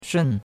shun4.mp3